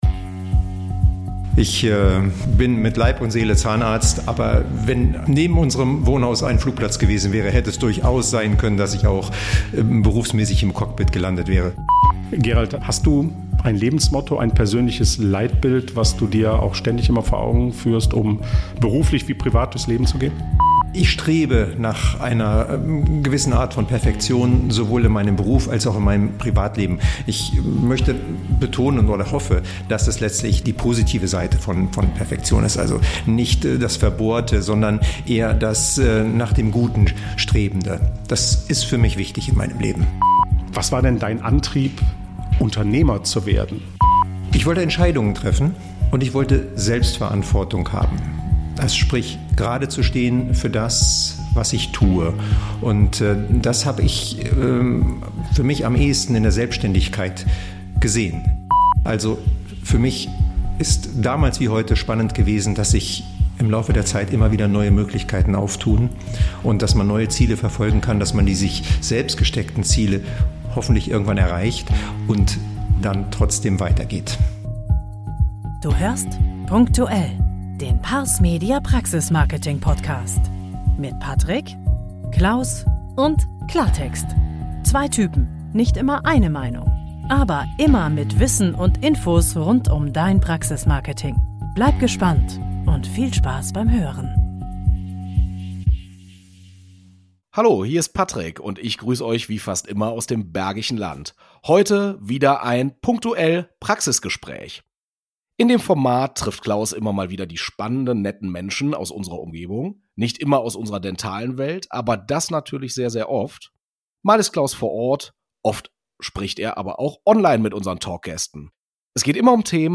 Beschreibung vor 2 Jahren Unser Praxisgespräch hat dieses Mal in Hannover stattgefunden.